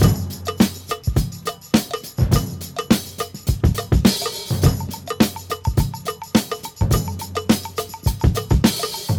104 Bpm 2000s Jazz Breakbeat F# Key.wav
Free breakbeat - kick tuned to the F# note.